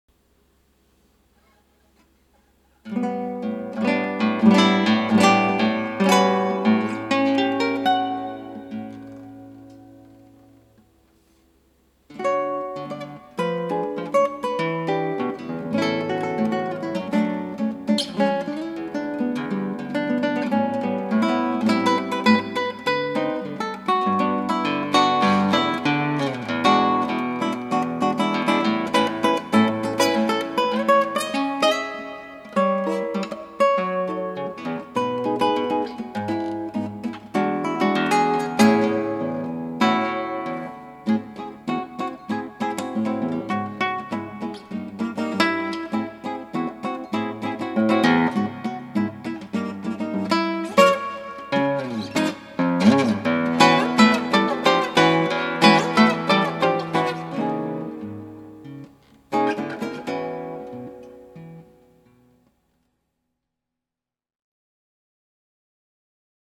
クラシックギター　ストリーミング　コンサート
で、ちょっと頭のトコだけ弾いてみました。